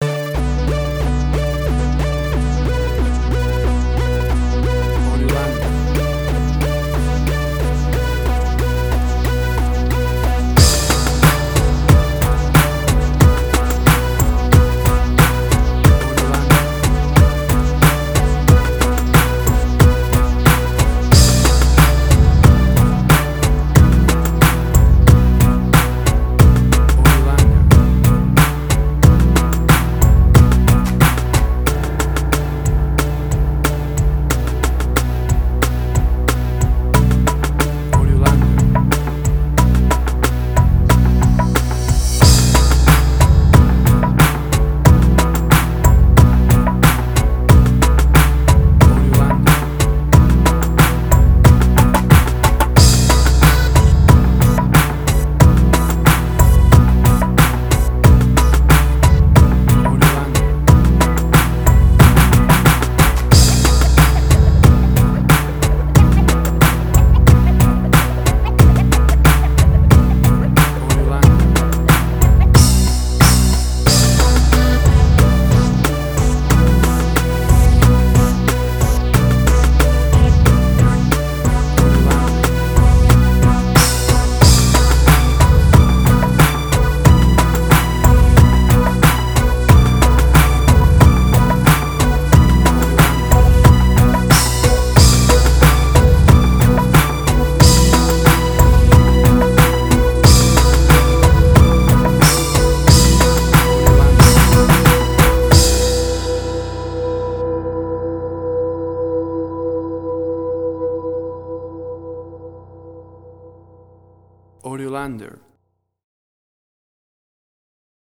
Suspense, Drama, Quirky, Emotional.
Tempo (BPM): 92